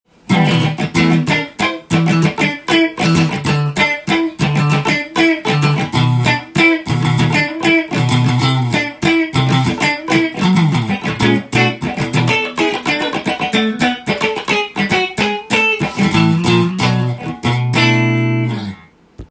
フェンダーのジャズマスターです！
太すぎず細すぎない丁度良い音になります。
試しに弾いてみました
クリーントーンは、芯がある音がします。